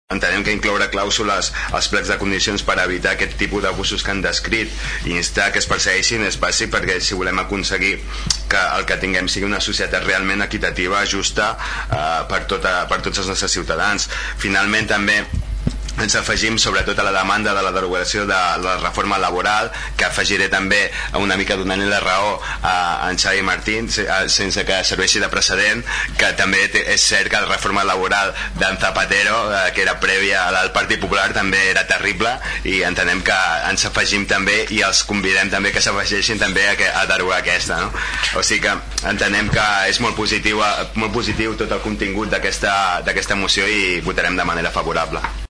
El ple de l’Ajuntament de Tordera aprova una moció al voltant de les empreses multiserveis
Per part de SOM Tordera, el regidor Salva Giralt es mostrava completament d’acord amb el contingut de la moció, per tal d’aconseguir una societat equitativa per a tots els ciutadans.